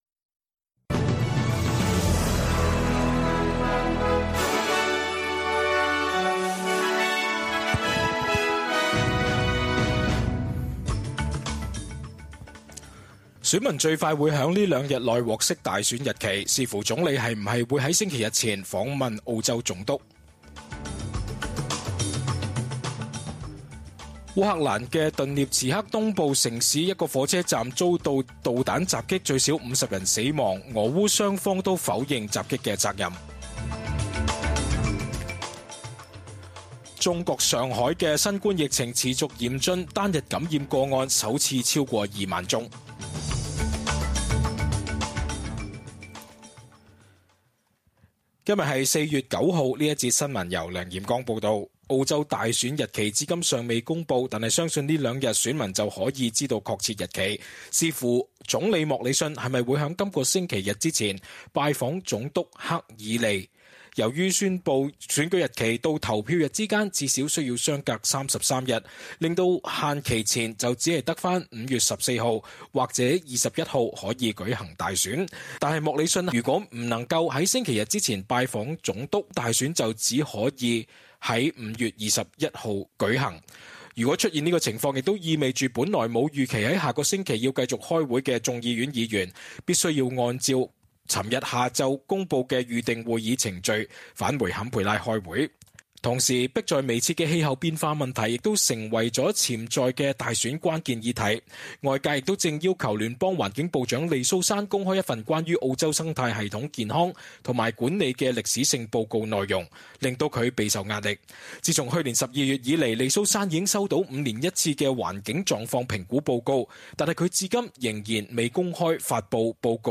SBS 廣東話節目中文新聞 Source: SBS Cantonese
cantonese_news_0904_mp3.mp3